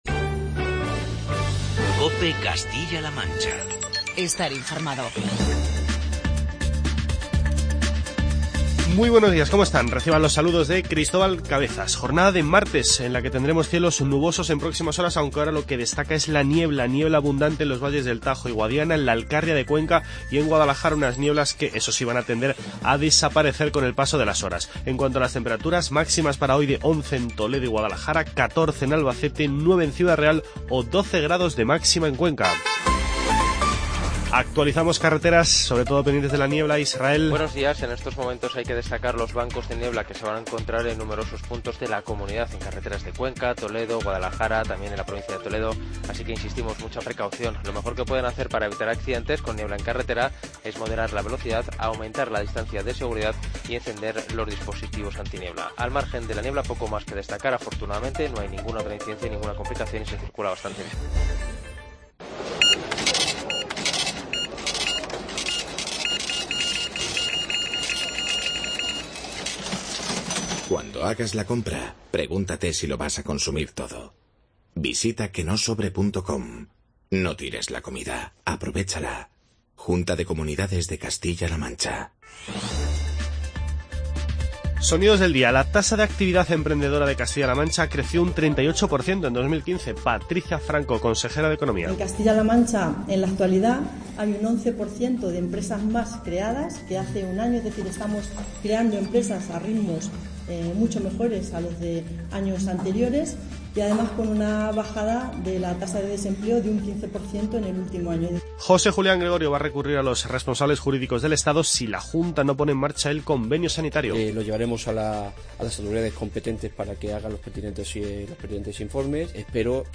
Informativo regional y provincial